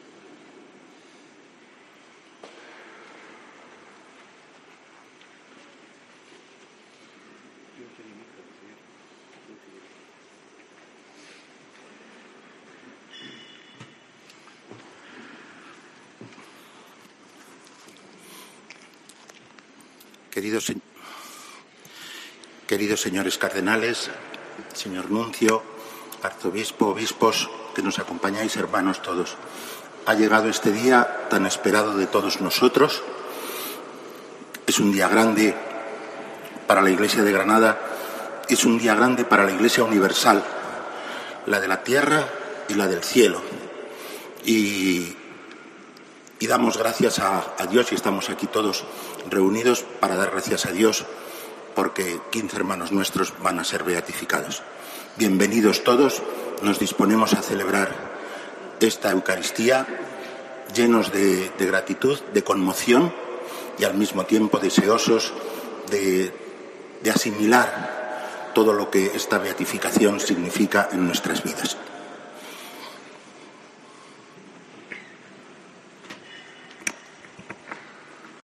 Así ha saludado el arzobispo a los asistentes a la ceremonia de beatificación